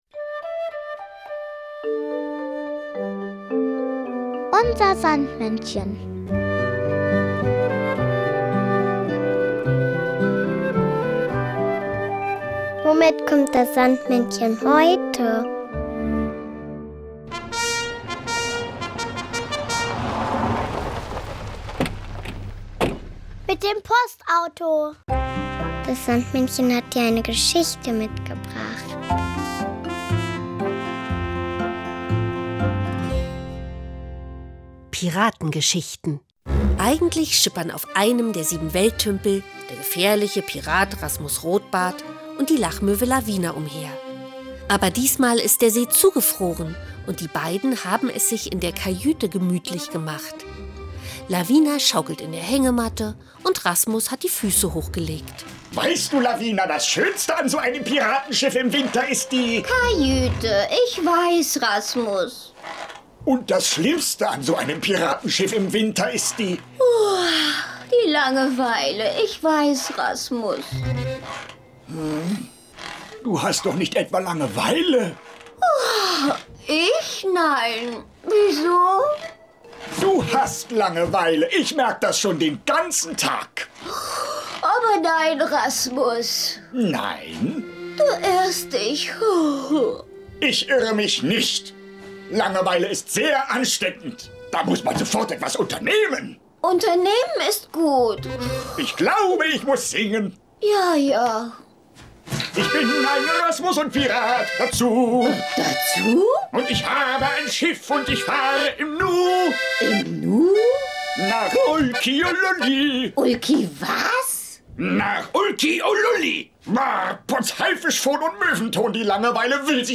UNSER SANDMÄNNCHEN bringt die Geschichten der beliebten Sandmannserien zum Hören mit.